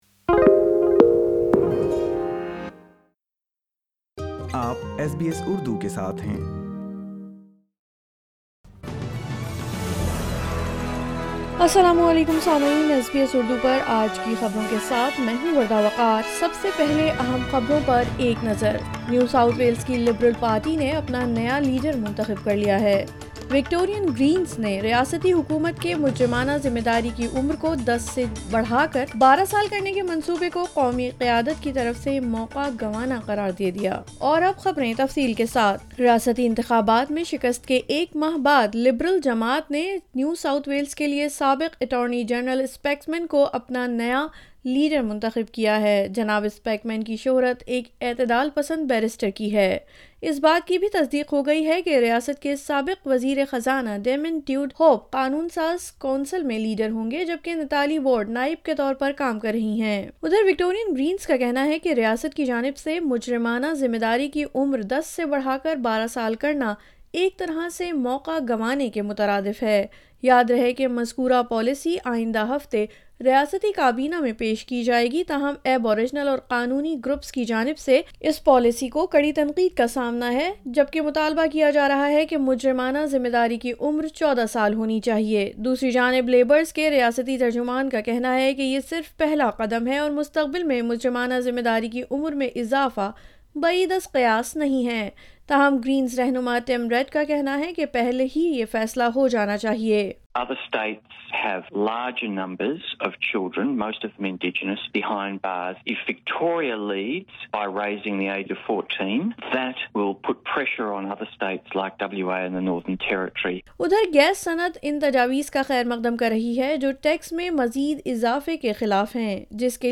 اردو خبریں 21 اپریل 2023: لکمبا مسجد میں نماز عید کی ادائیگی ۔